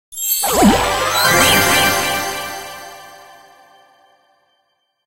blingbox_5_open_01.ogg